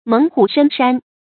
猛虎深山 注音： ㄇㄥˇ ㄏㄨˇ ㄕㄣ ㄕㄢ 讀音讀法： 意思解釋： 喻勇武而勢盛。